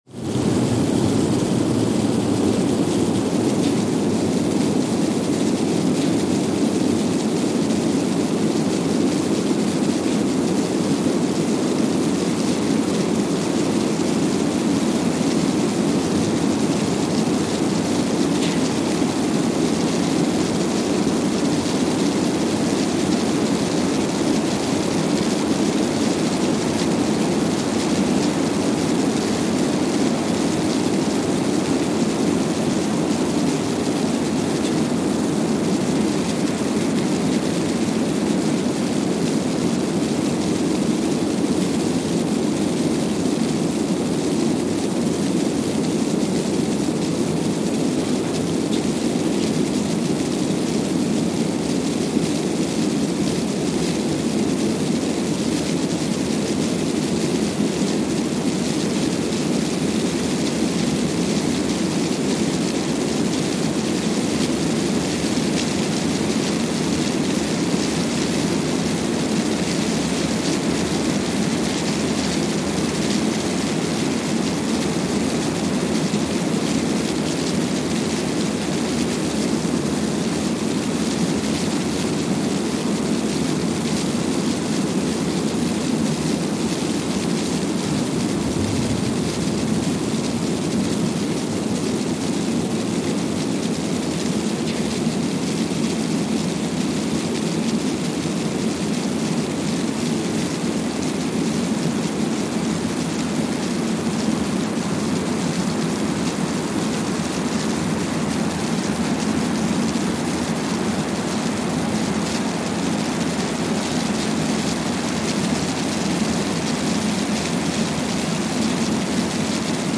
Kwajalein Atoll, Marshall Islands (seismic) archived on October 23, 2020
Sensor : Streckeisen STS-5A Seismometer
Speedup : ×1,000 (transposed up about 10 octaves)
Loop duration (audio) : 05:45 (stereo)